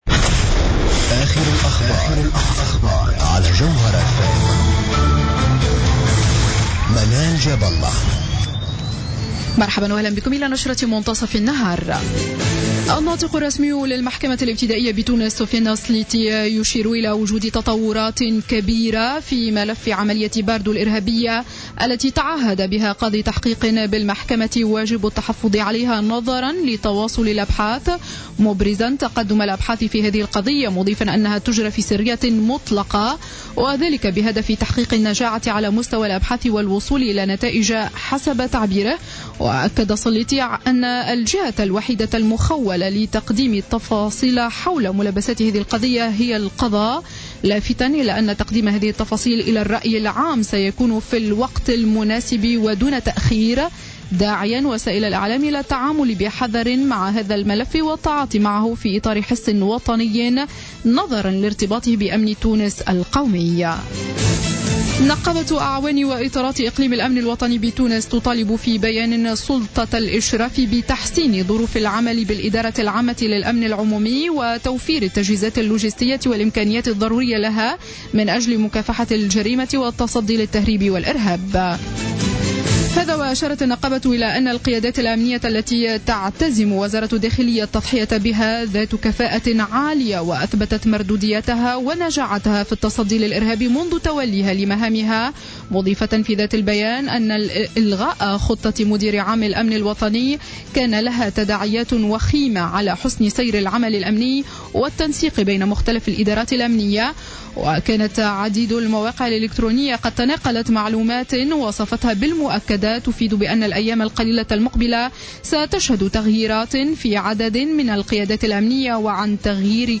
نشرة أخبار منتصف النهار ليوم السبت 21 مارس2015